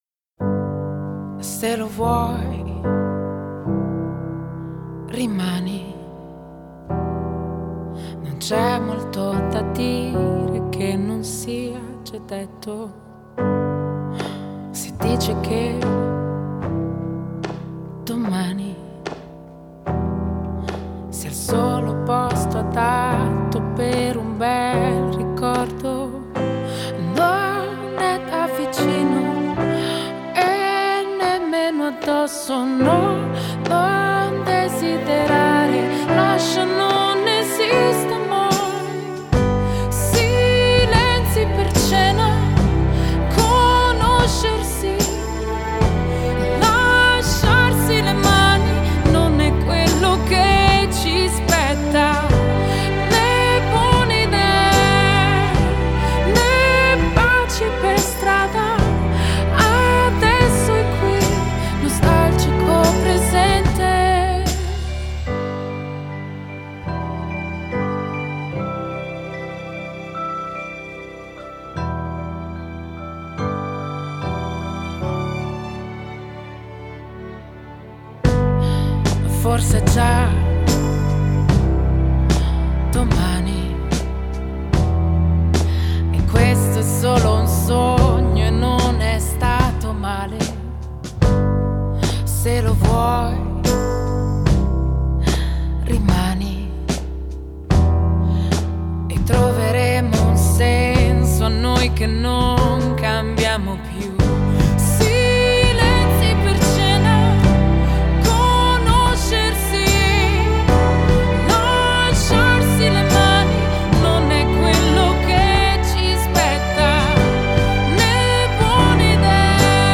Intervistata ai microfoni di AntennaRadioEsse